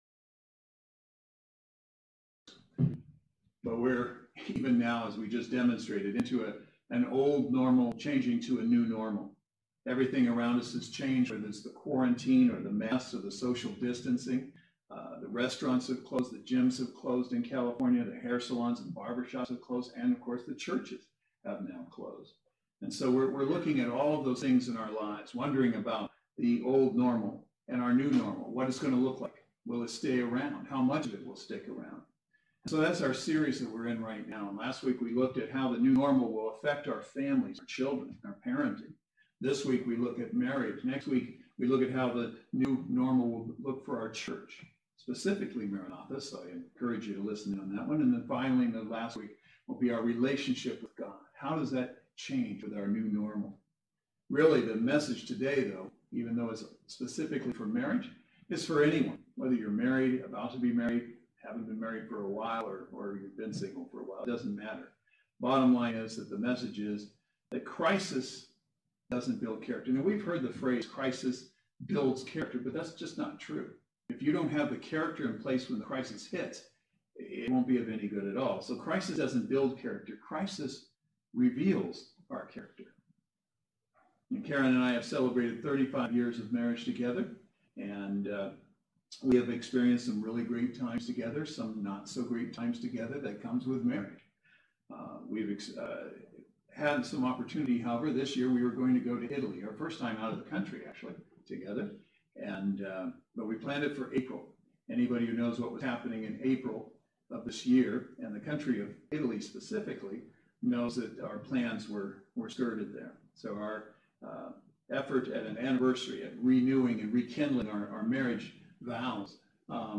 Saturday Worship Service